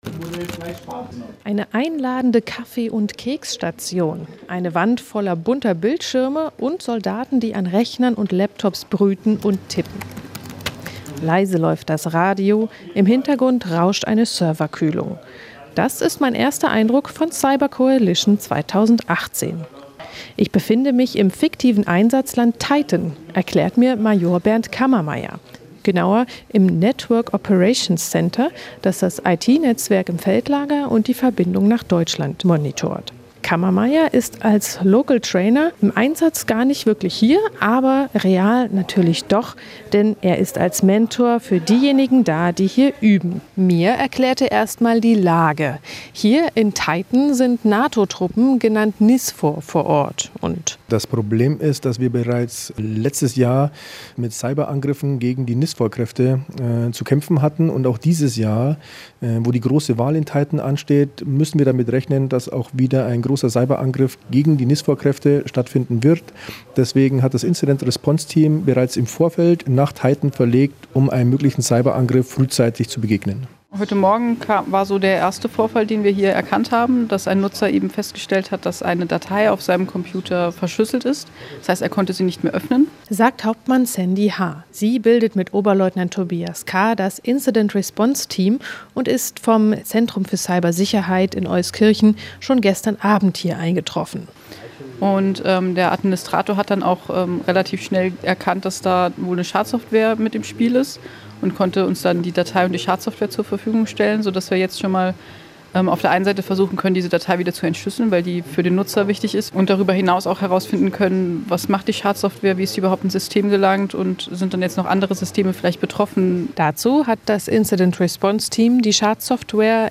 Auch 2018 haben Angehörige des Organisationsbereich CIRCyber- und Informationsraum an der Übung teilgenommen. Die Audioreportage gibt einen Einblick in die damaligen Geschehnisse.